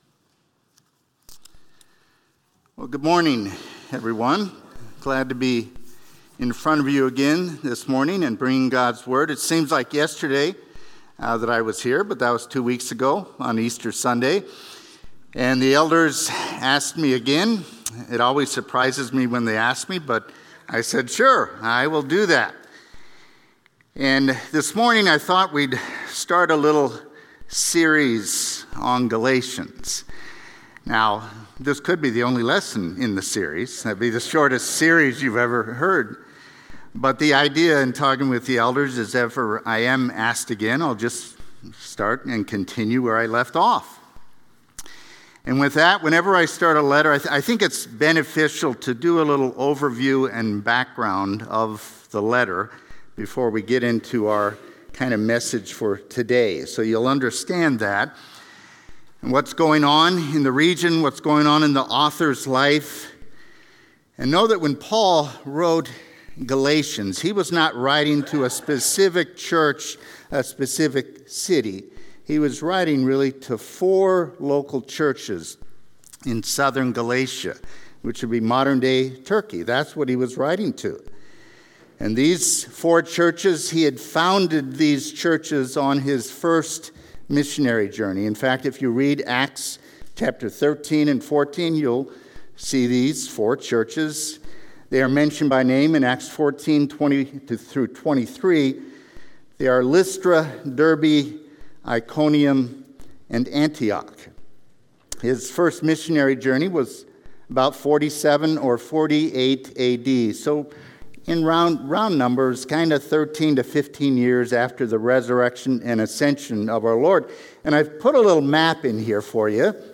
A message from the series "Fruit of the Spirit."